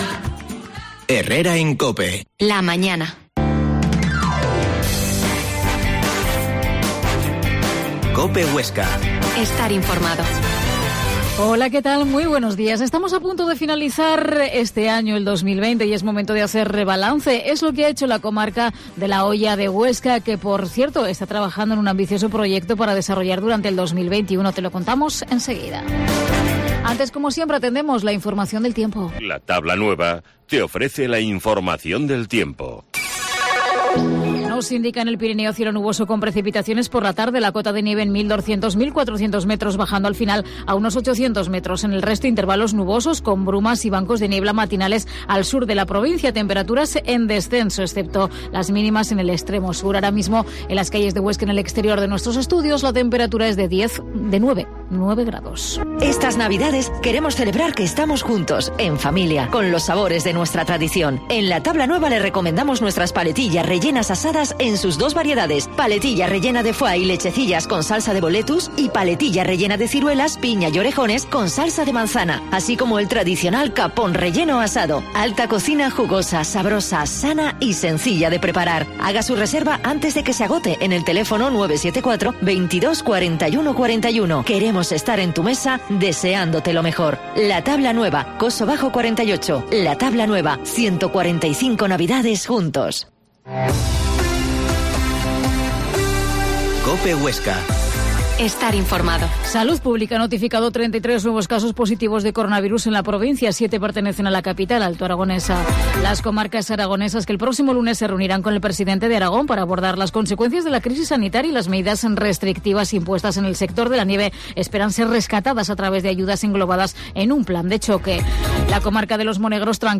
Herrera en COPE Huesca 12.50h Entrevista al Presidente de la Comarca de la Hoya Jesús Alfaro